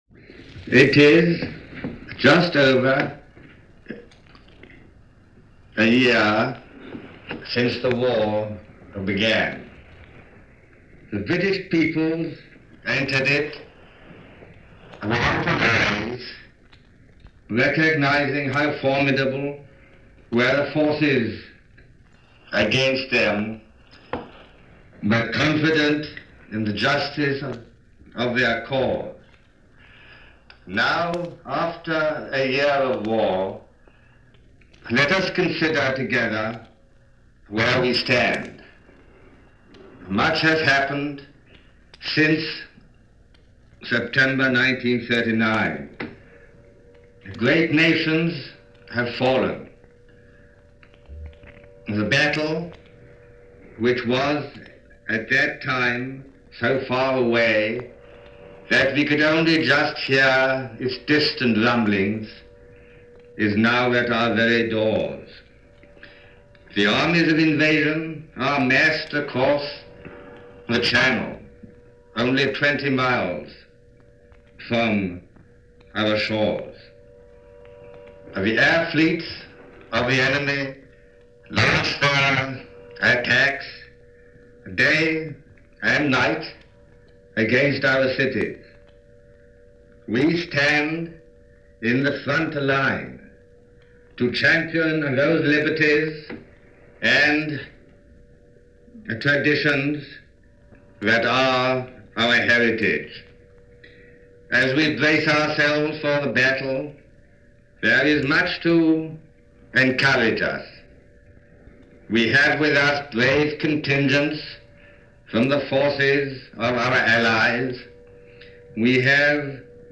Address by King George VI - September 23, 1940
King George VI addresses the Commonwealth on September23, 1940 - giving the nation a pep talk, in the midst of stepped up raids by Germany.